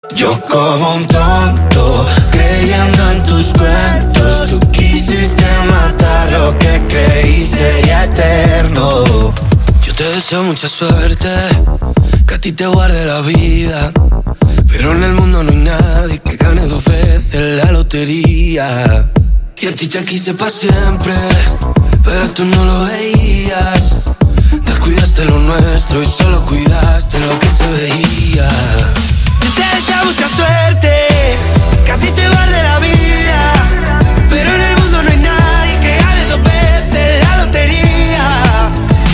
Categoría Rap